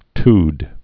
(td, tyd)